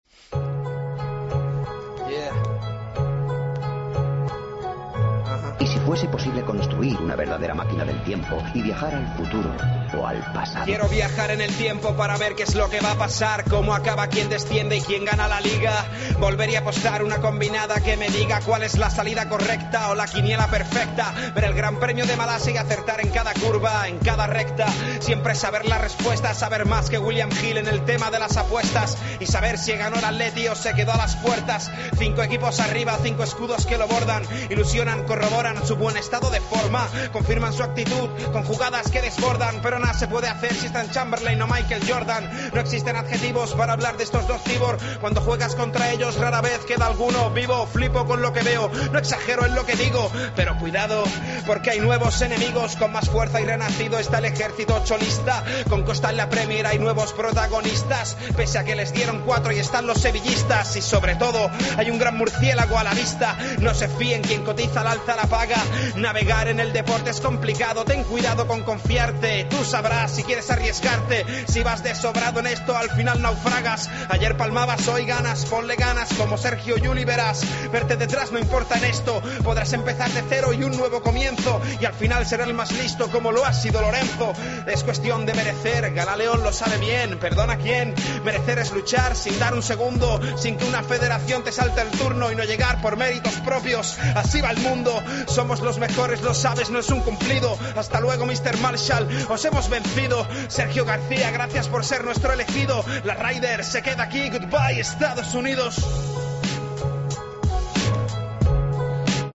El rap